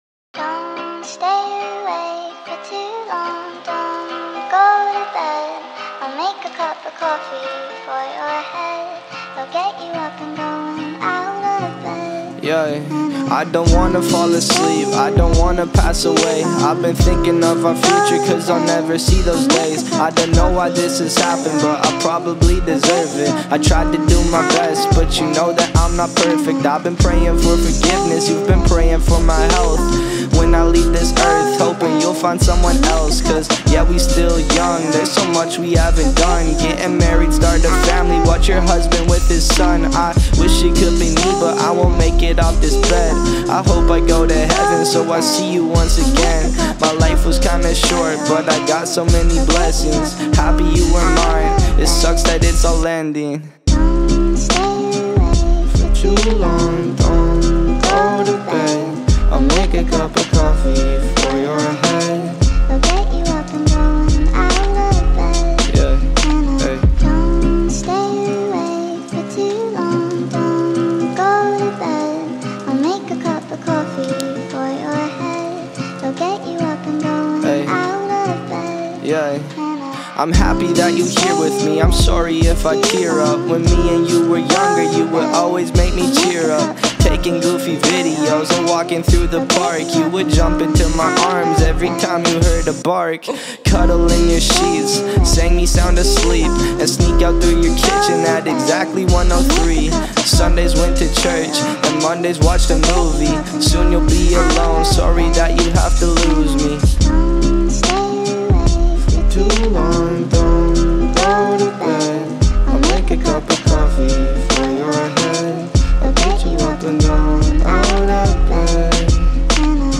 در سبک Lo‑fi Hip‑hop / Emo‑rap ساخته شده است
فضای مینیمال و احساسی‌اش